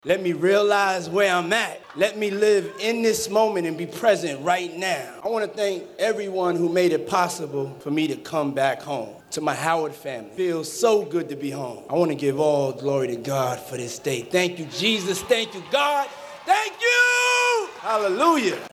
Dr. Combs speech, which was a little over 40 minutes, was personal, moving, touching, at times a little funny but overall motivational and extremely inspirational. His delivery was filled with the “Diddy Charisma” you would come to expect, however you could tell the events of the day had him a little nervous.
diddy-giving-thanks.mp3